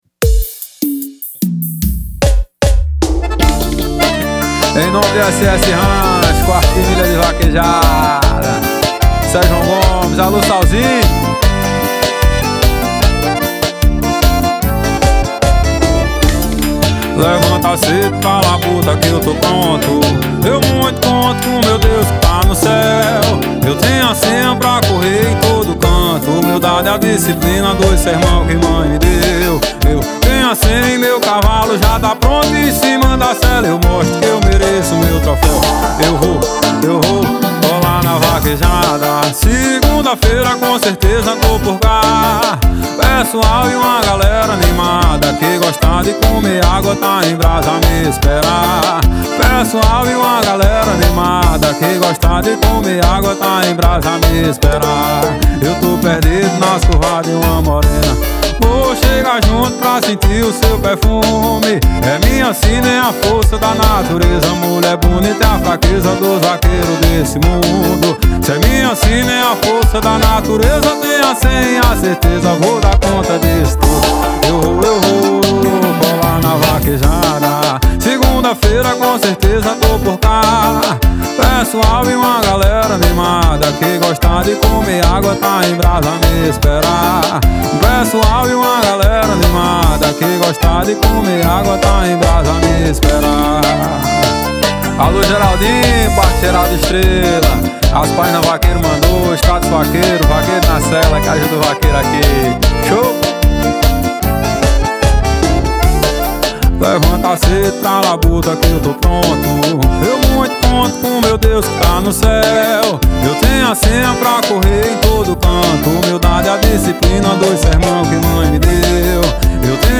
2024-02-14 17:56:19 Gênero: Forró Views